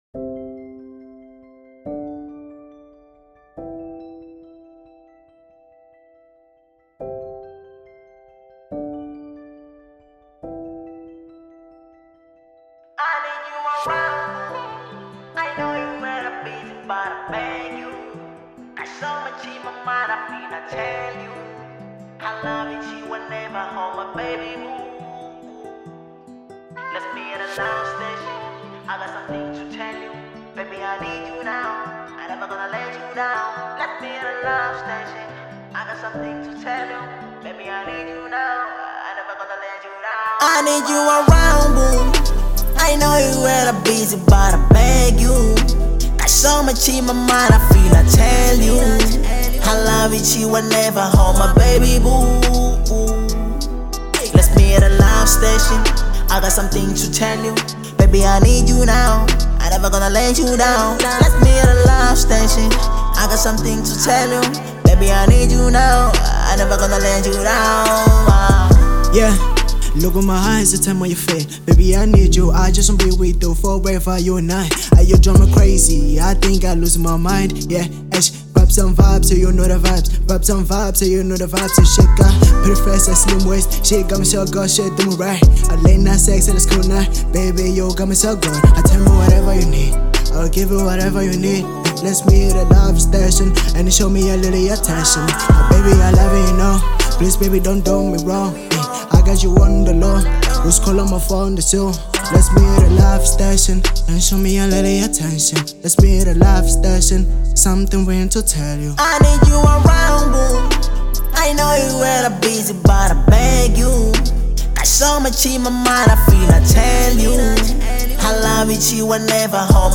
02:49 Genre : Hip Hop Size